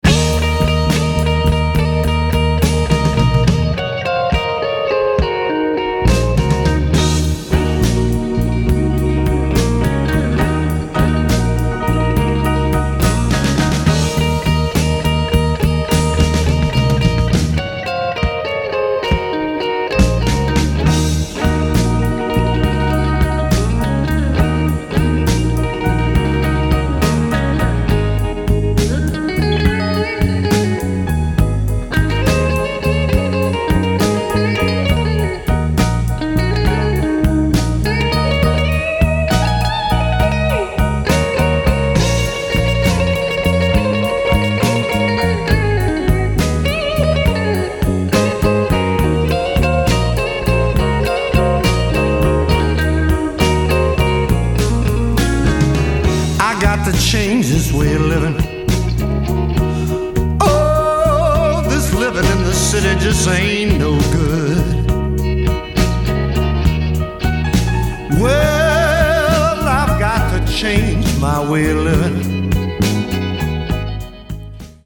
Chicago influenced harp playing